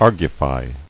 [ar·gu·fy]